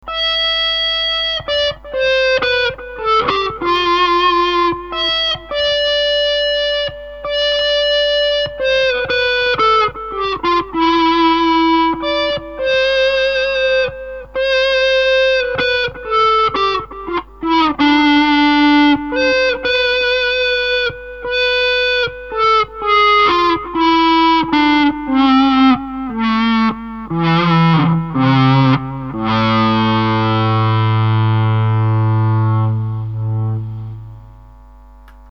slow gear everdrive.mp3